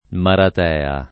[ marat $ a ]